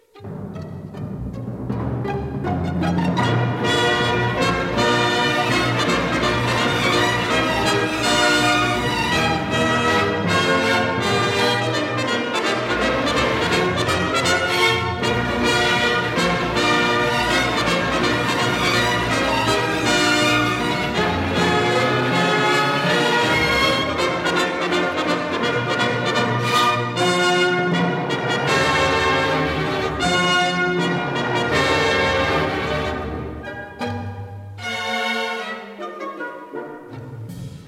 conductor
1958 stereo recording